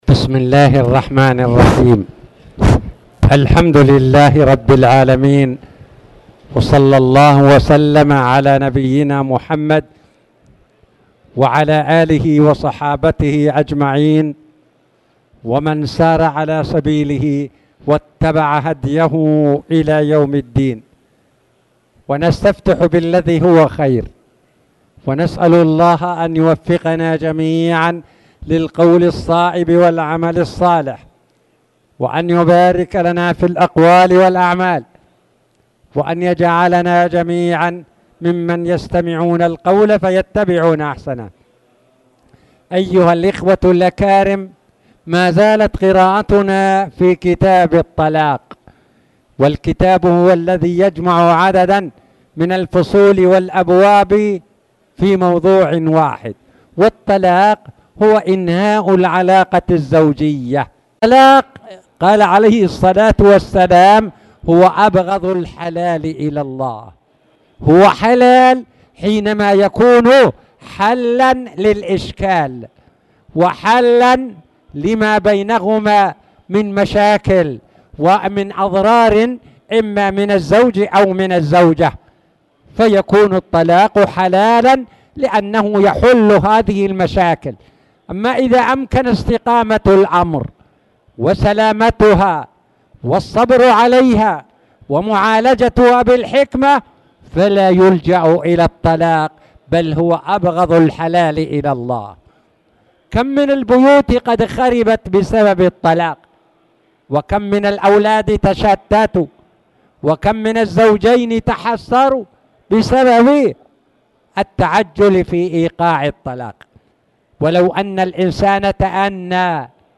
تاريخ النشر ١٥ جمادى الآخرة ١٤٣٨ هـ المكان: المسجد الحرام الشيخ